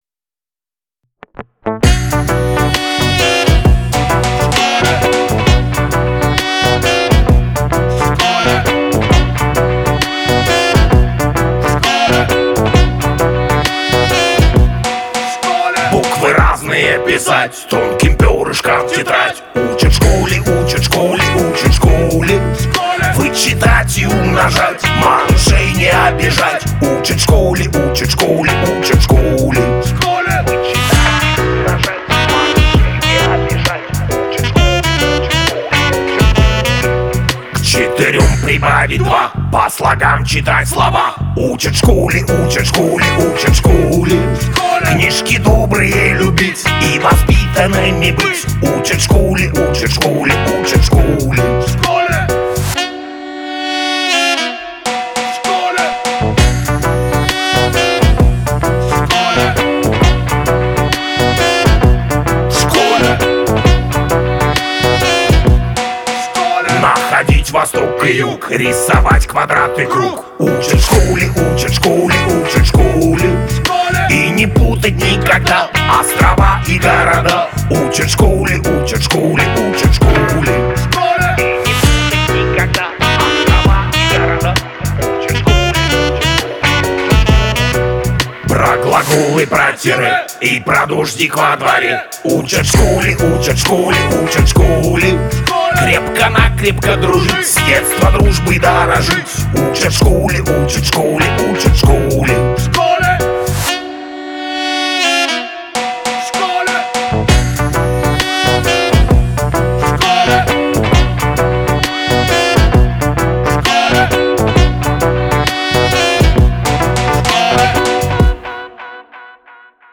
Качество: 320 kbps, stereo
песни для детишек